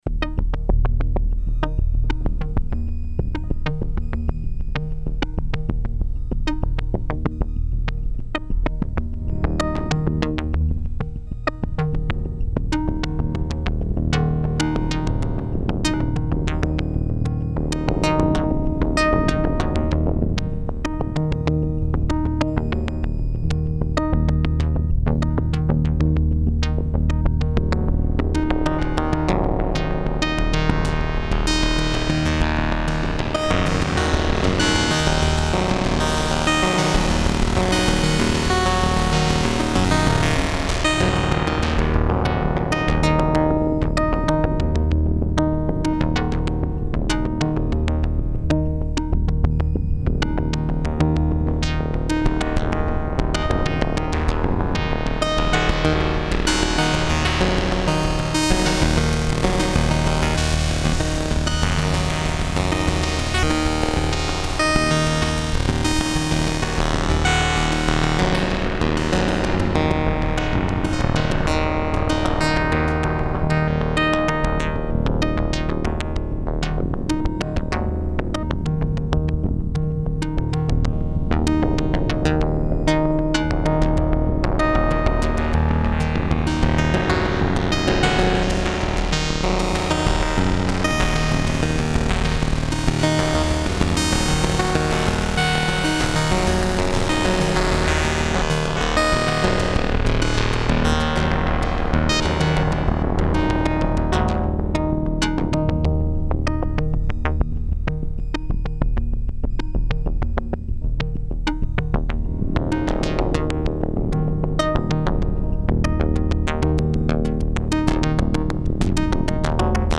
Zwei Soundbeispiele aus meinen Anfangstagen mit dem Rev2.